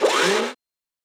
smallMove.ogg